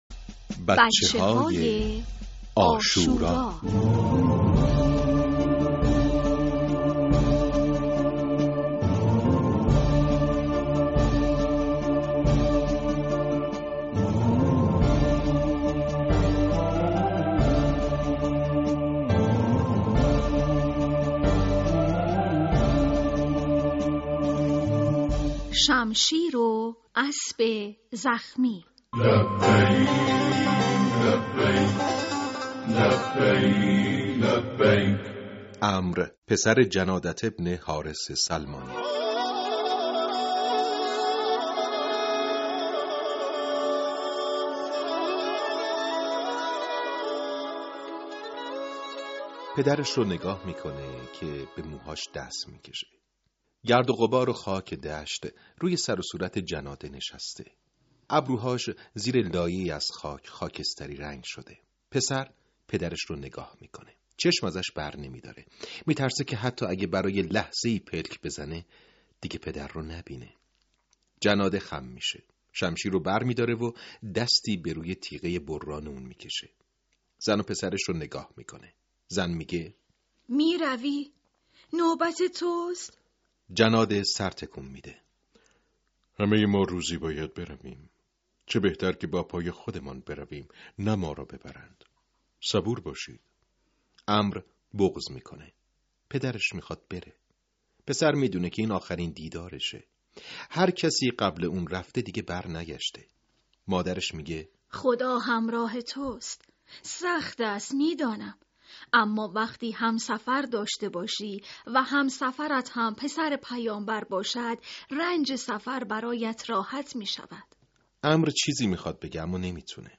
صوتی | بچه‌های عاشورا (03) دانلود صوت بفرمایید قصه بچه‌های عاشورا “شمشیر و اسب زخمی” این قسمت: عمر پسر جنادة ابن حارث سلمان این قصه رو همراه با فرزندتون بشنوید. # بچه های عاشورا # قصه # ماه محرم الحرام # حضرت امام حسین علیه السلام # قصه کودک # کتاب صوتی # پادکست